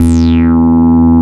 70.10 BASS.wav